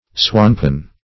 Search Result for " swanpan" : The Collaborative International Dictionary of English v.0.48: Swanpan \Swan"pan\, n. [Cf. Schwanpan .] The Chinese abacus; a schwanpan.